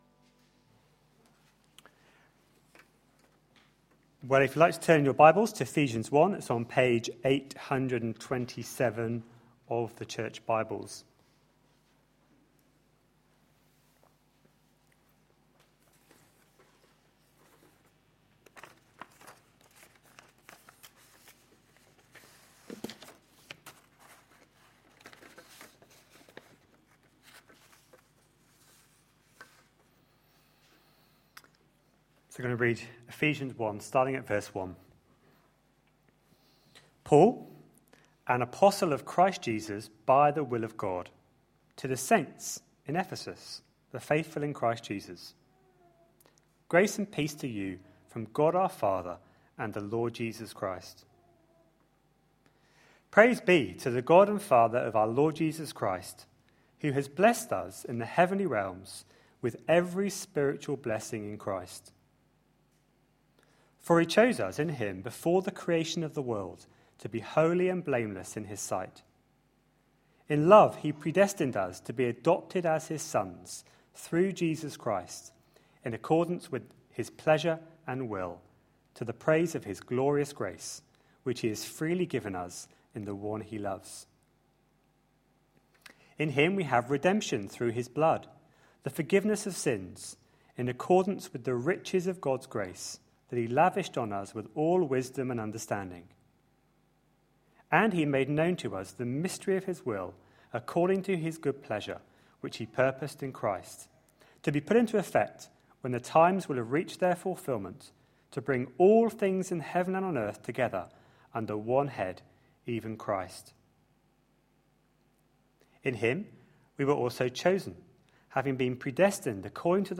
A sermon preached on 26th May, 2013, as part of our Ephesians series.